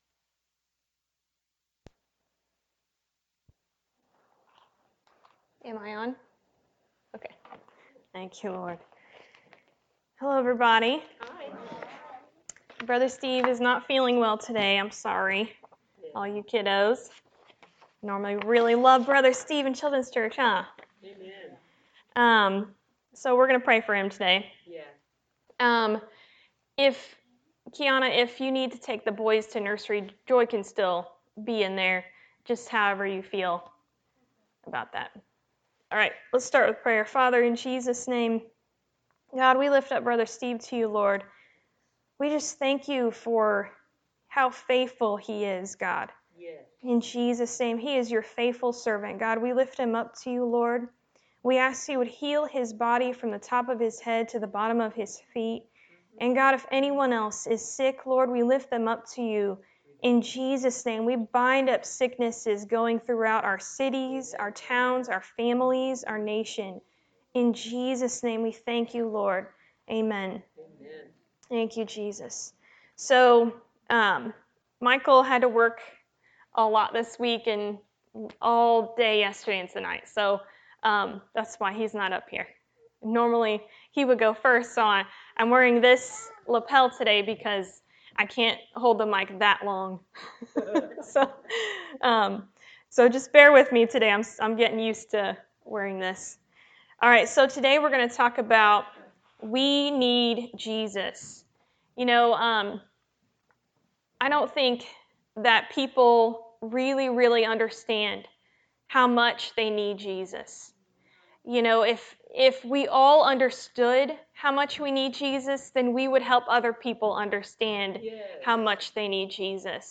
Sunday-Sermon-for-November-5-2023.mp3